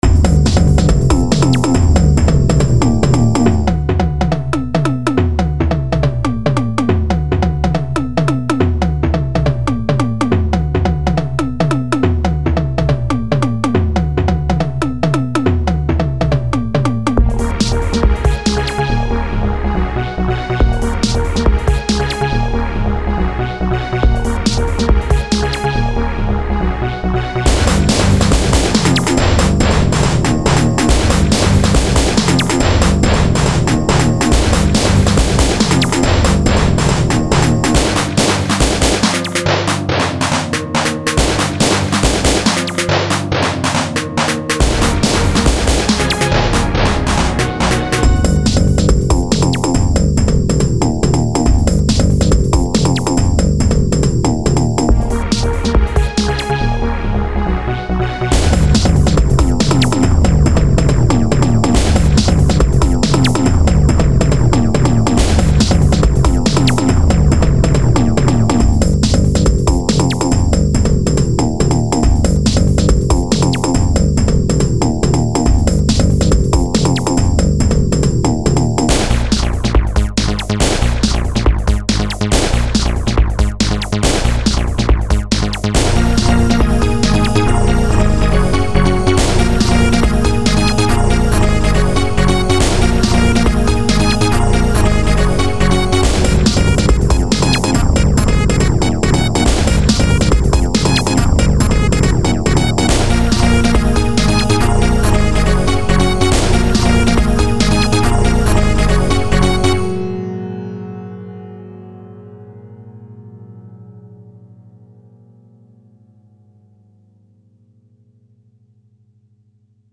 My next song, I am not sure but I personaly miss something here, because it doesn't sound very good.
This song lacks pads and some pass moments,ya know,from calm to extreme,it needs some sort of bridge.